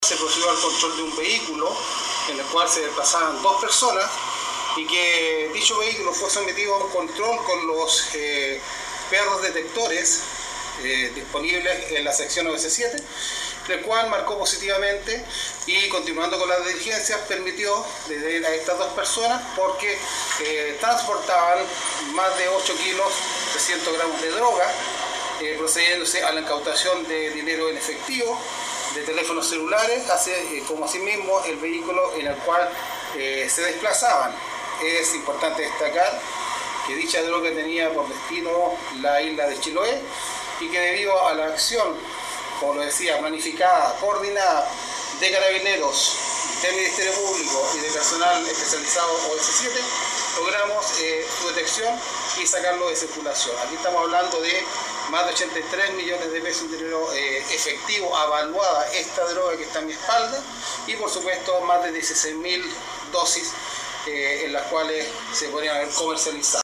Las diligencias se concretaron mediante la coordinación de la sección OS7 Llanquihue, Carabineros y el Ministerio Público, donde  funcionarios llevaron a cabo un control a un vehículo en la ruta 5 sur, a la altura de la comuna de San Pablo, en el que viajaban dos personas, detalló el coronel Gustavo Saavedra, Prefecto de Carabineros de Osorno.
15-CORONEL-GUSTAVO-SAAVEDRA-PREFECTO.mp3